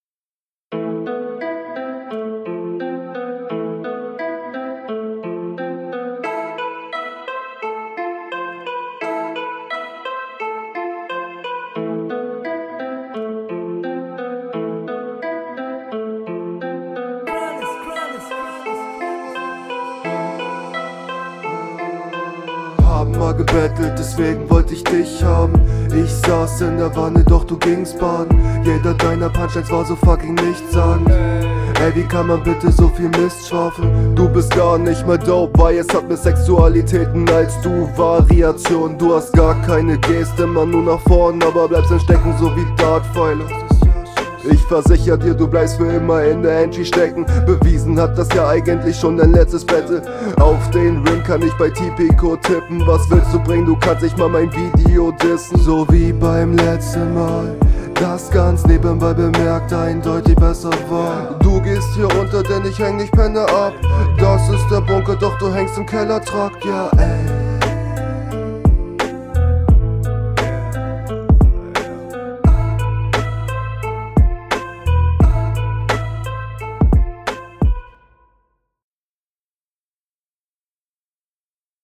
Durch die Adlibs kriegt das Ganze eine richtig nice Stimmung, sehr nice für den Beat …
Ganz cooler Beat.